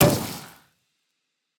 Minecraft Version Minecraft Version snapshot Latest Release | Latest Snapshot snapshot / assets / minecraft / sounds / mob / pufferfish / sting2.ogg Compare With Compare With Latest Release | Latest Snapshot
sting2.ogg